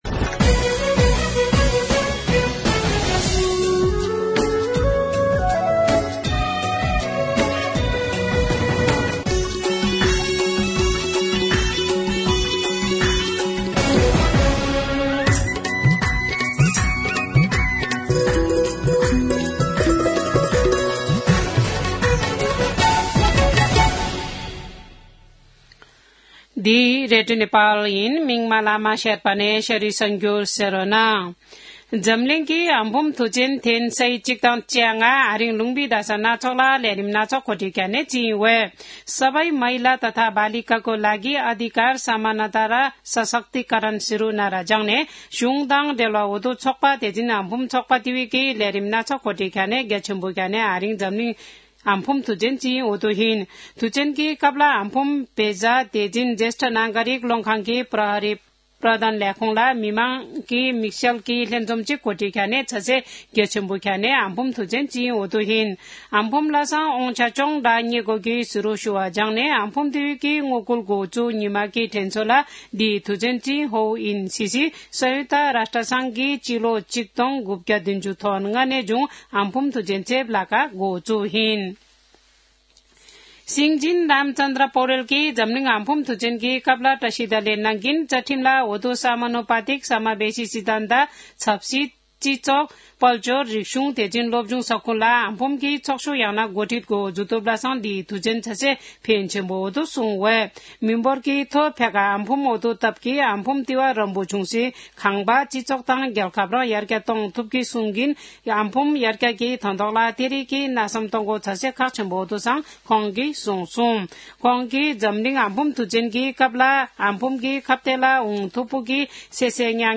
शेर्पा भाषाको समाचार : २५ फागुन , २०८१
Sherpa-News.mp3